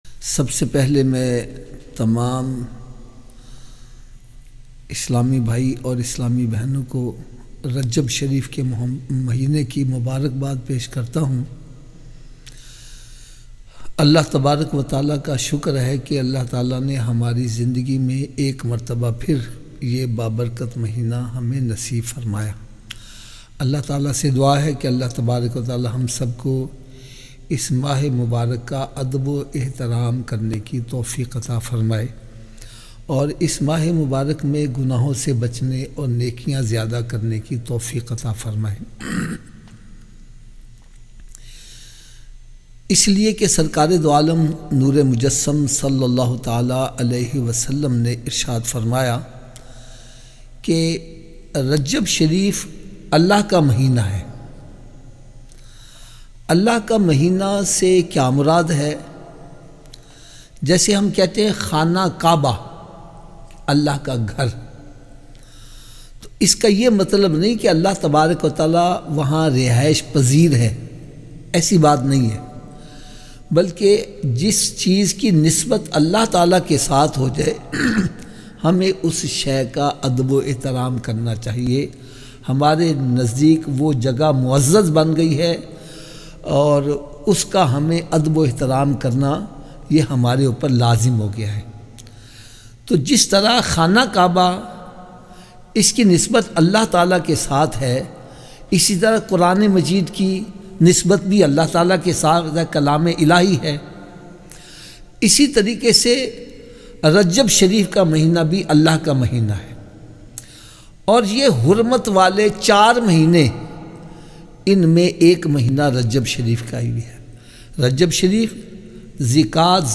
Rajab-Ke-Mahine-Ki-Fazilat-Rajab-Ka-Bayan.mp3